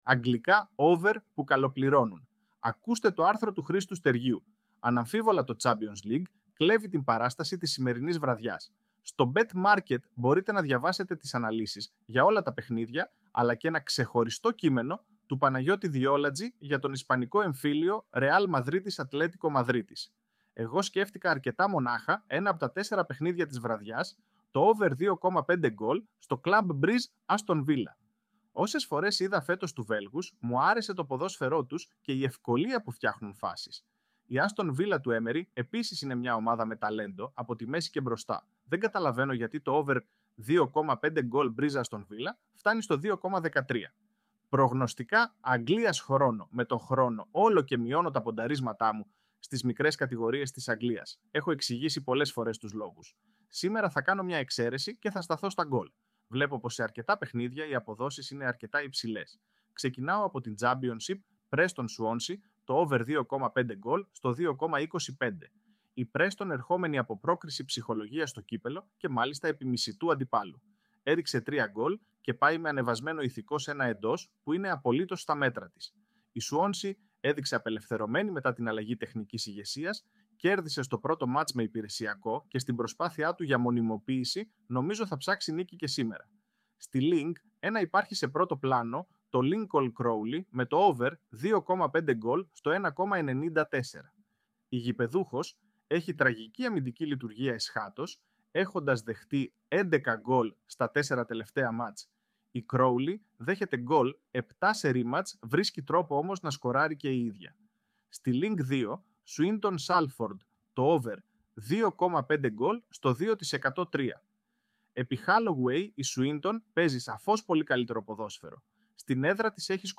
με την βοήθεια του AI!